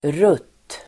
Uttal: [rut:]